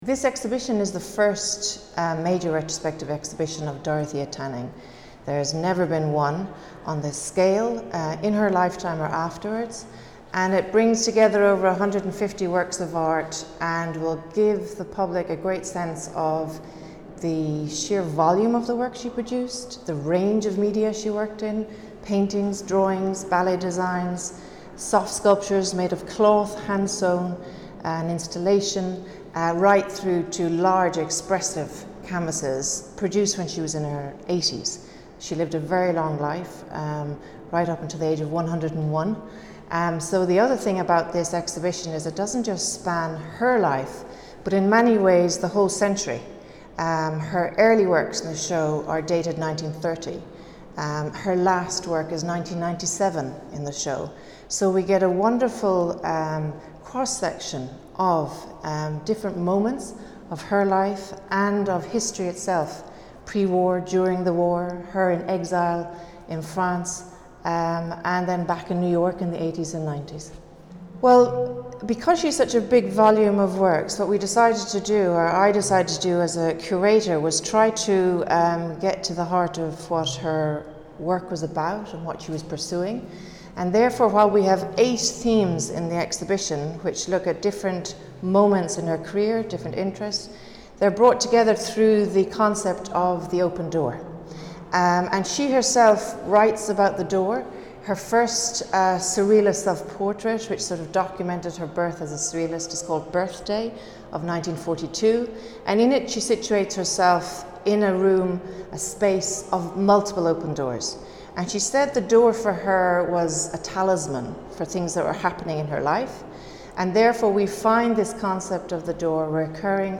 Declaraciones en audio del director del Museo, Manuel Borja-Villel (.mp3 / 6.2 MB)